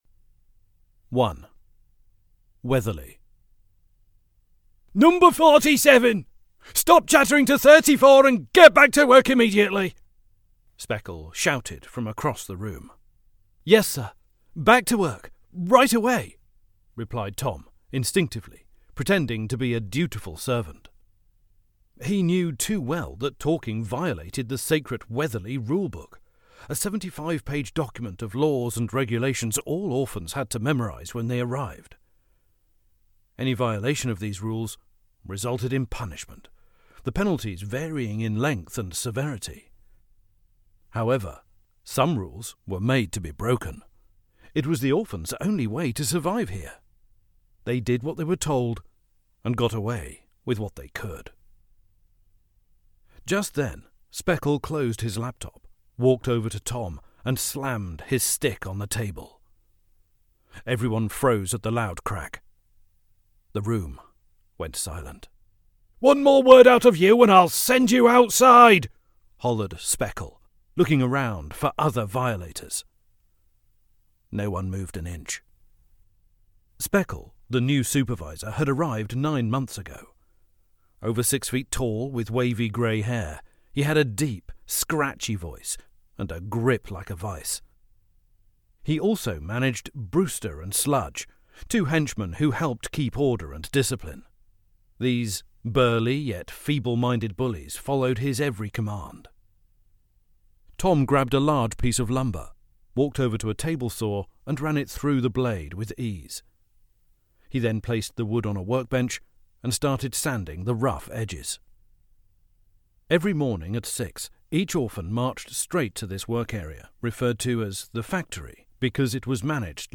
Britfield Audiobook Chapters 1 & 2